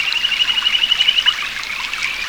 Index of /90_sSampleCDs/Roland LCDP11 Africa VOL-1/SFX_Afro Jungle/SC _Afro Jungle
AN  CRICKE04.wav